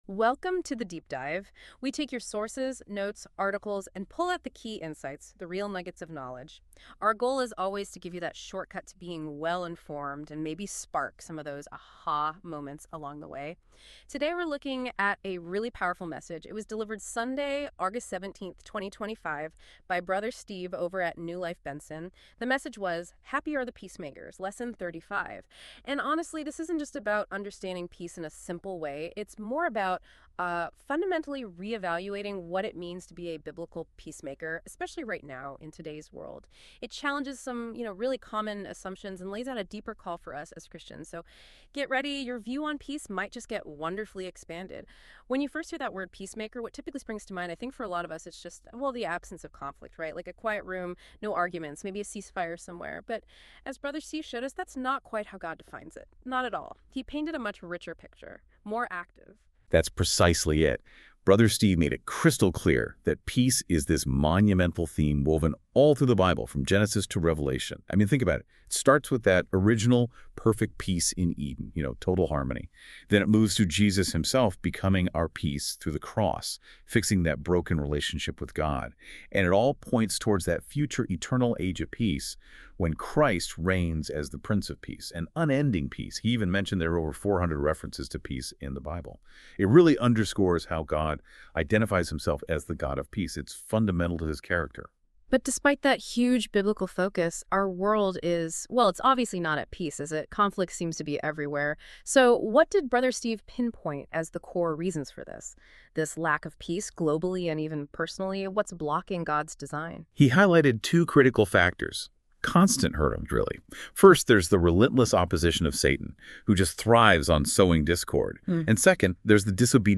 Sermon on the Mount Lesson 35 Podcast.mp3